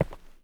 mining sounds
ROCK.3.wav